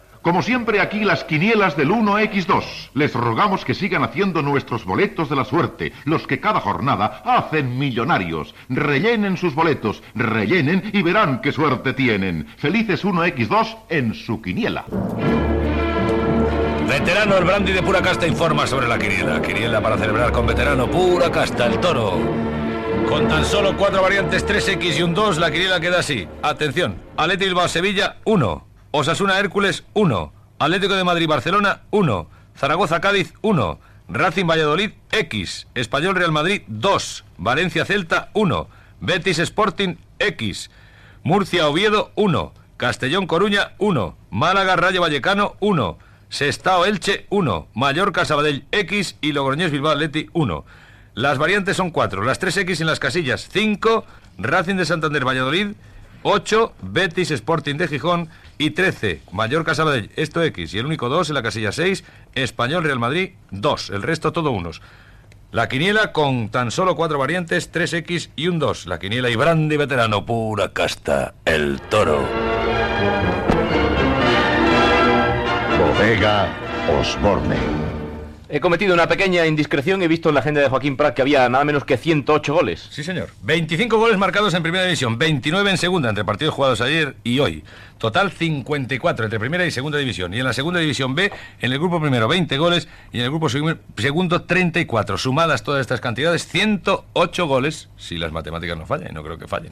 Publicitat, resultats de la travessa de futbol masuclí, publicitat i informació del nombre de gols marcats en algunes des les competicions aquella jornada
Esportiu